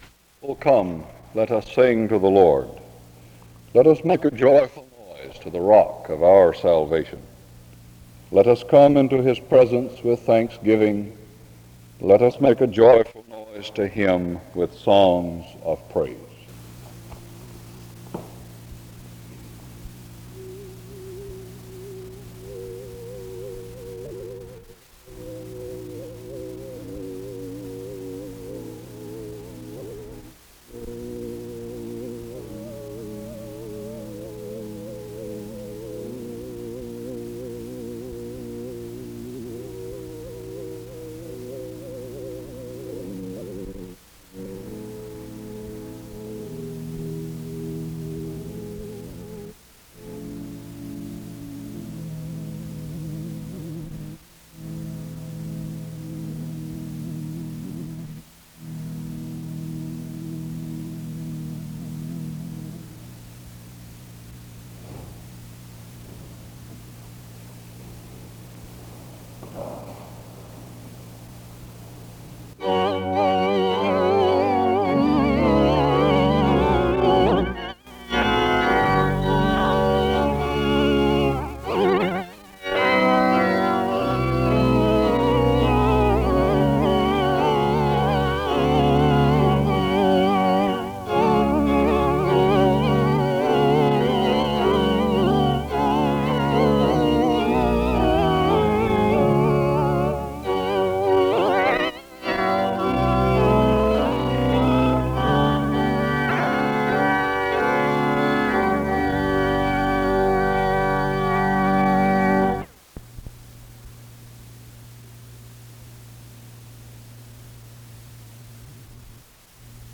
The service opens with a reading of scripture and music from 0:00-2:00. A prayer is offered from 2:12-3:32. An introduction to the speaker is given from 3:38-5:25.
SEBTS Chapel and Special Event Recordings SEBTS Chapel and Special Event Recordings